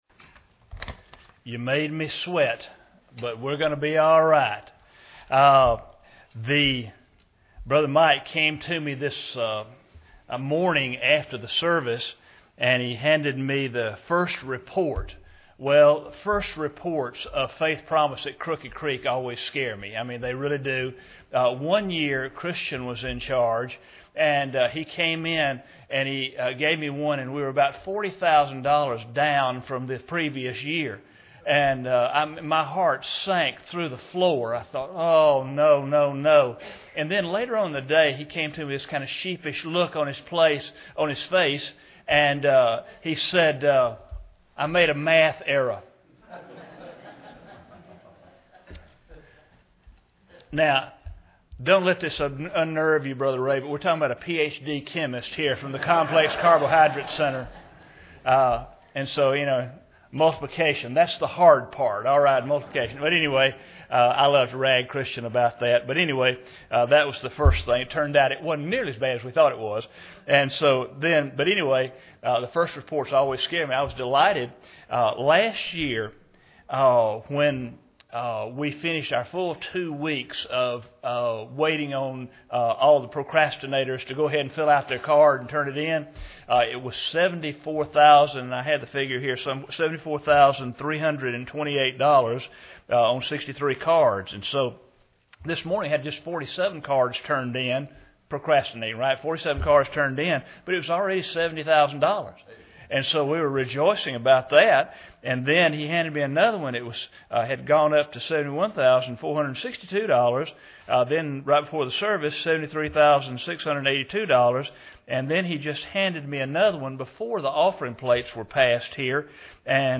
Series: 2013 Missions Conference
Service Type: Sunday Evening